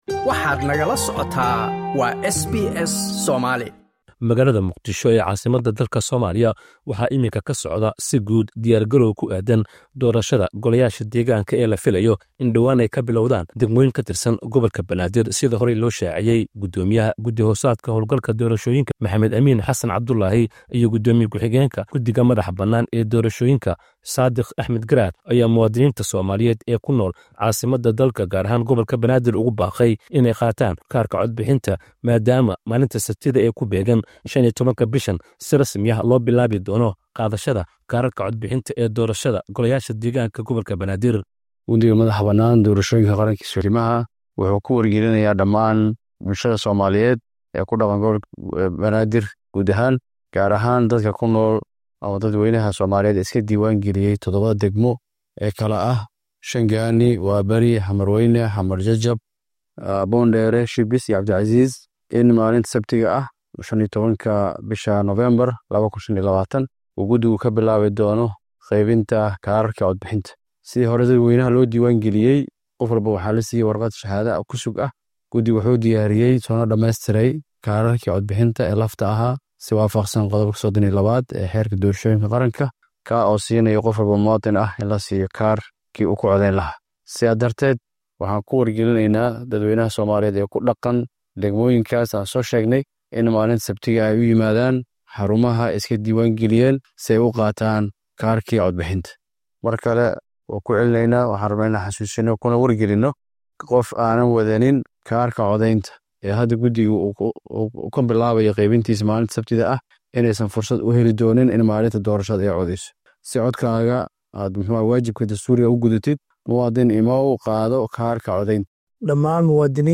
Somalia News - Warkii Somalia: 14 November 2025